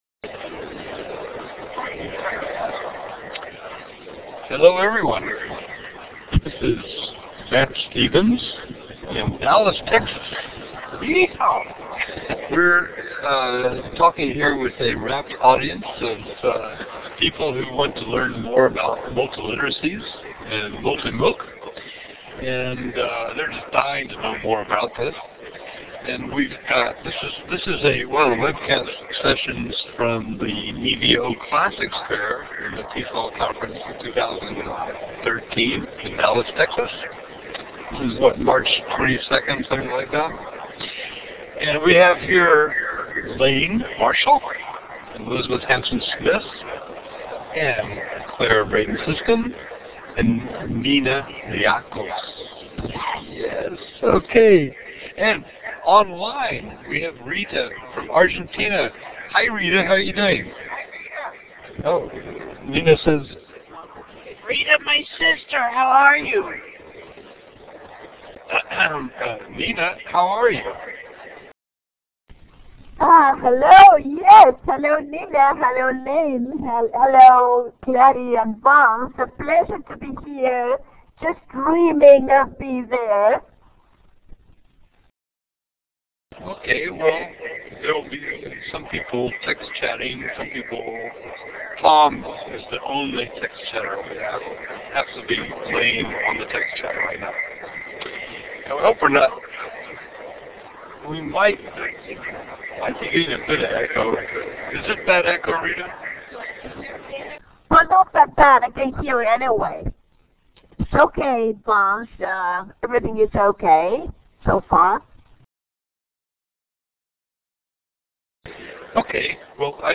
March 21-23, Learning2gether traveled to Dallas to help webcast live at from the Electronic Village and academic sessions taking place during the TESOL Convention